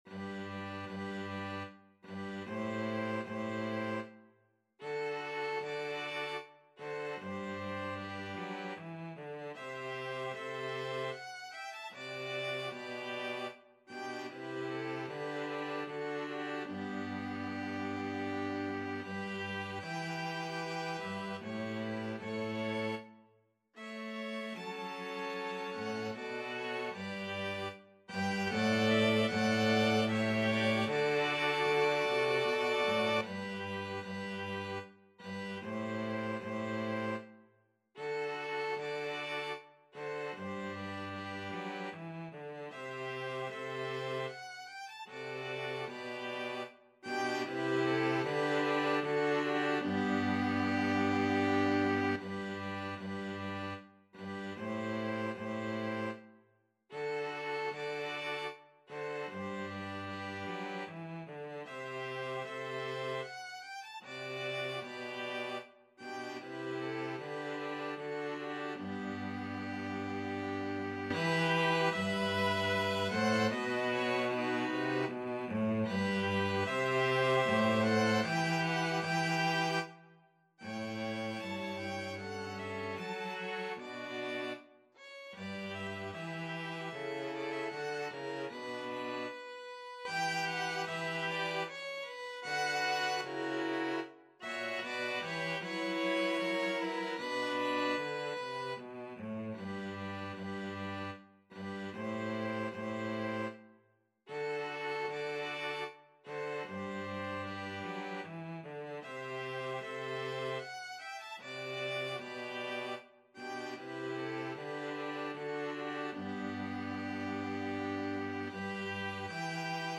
Free Sheet music for String Quartet
Violin 1Violin 2ViolaCello
G major (Sounding Pitch) (View more G major Music for String Quartet )
Larghetto = 76
3/4 (View more 3/4 Music)
String Quartet  (View more Easy String Quartet Music)
Classical (View more Classical String Quartet Music)